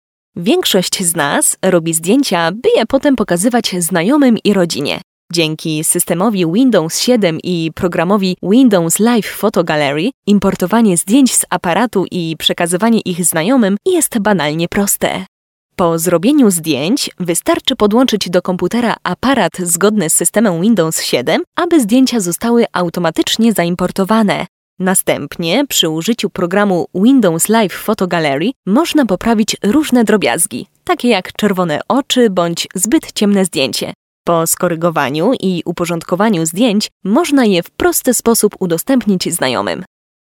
Sprecherin polnisch für TV / Rundfunk / Industrie.
Sprechprobe: Sonstiges (Muttersprache):
polish female voice over artist